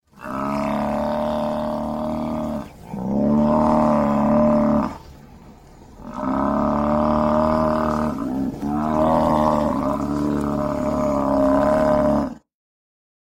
Звуки буйвола
Гул буйвола